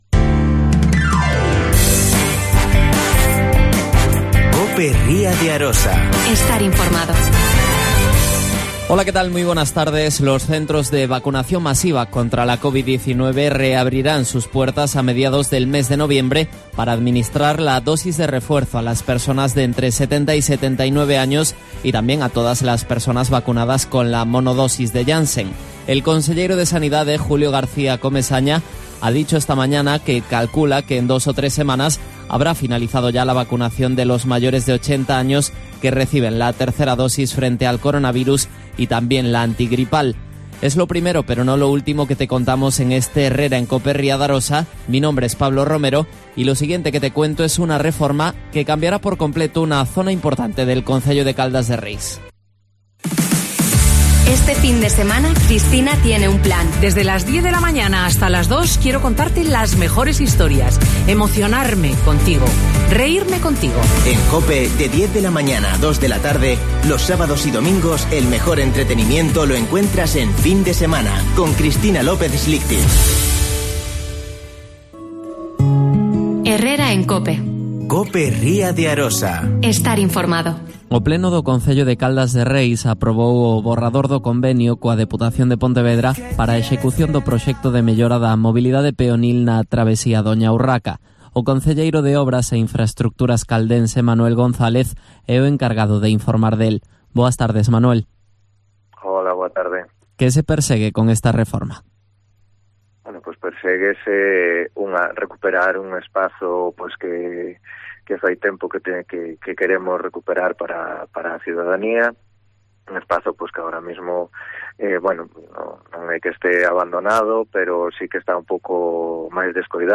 Manuel González. Concejal de Obras e Infraestructuras en el Ayuntamiento de Caldas de Reis.